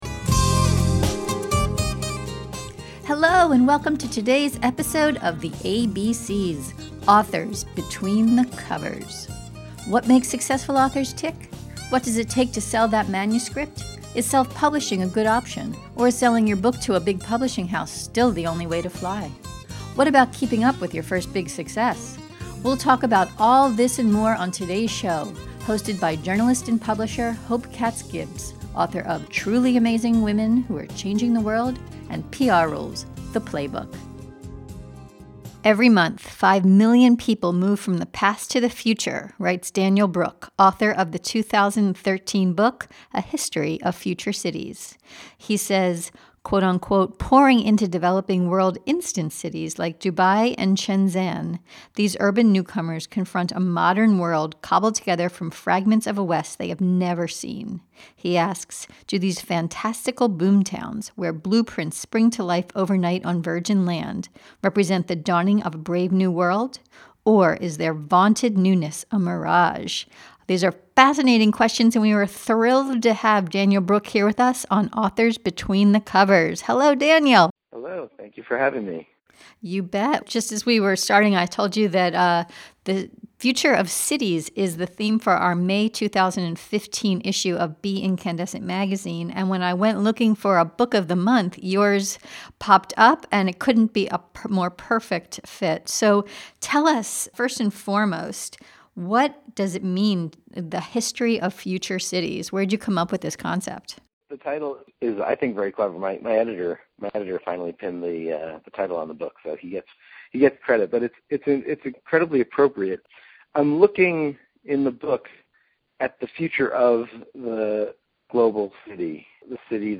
In this podcast interview